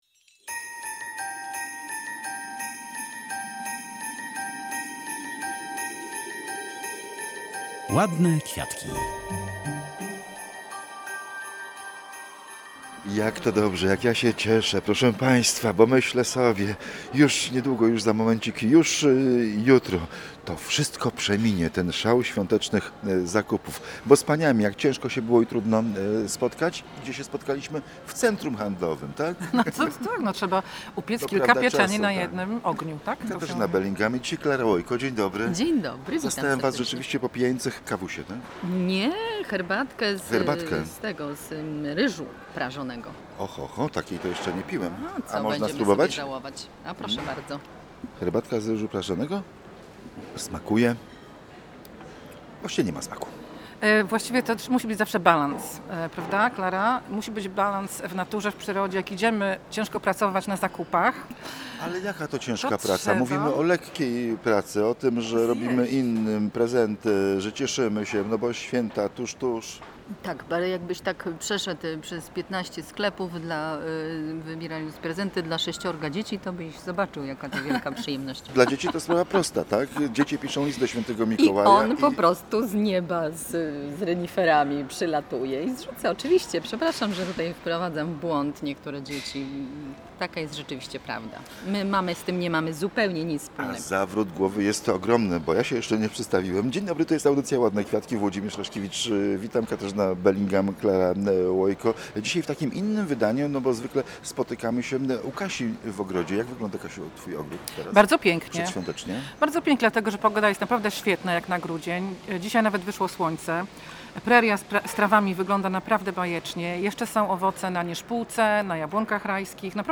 To rozmowa pełna uśmiechu i przedświątecznego dobrego nastroju.